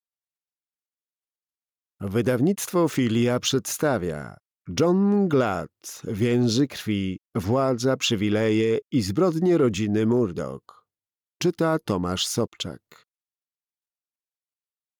Więzy krwi. Władza, przywileje i zbrodnie rodziny Murdaugh - John Glatt - audiobook